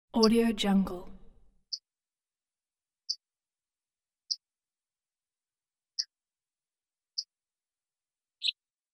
دانلود افکت صوتی جیک جیک آرام یک پرستو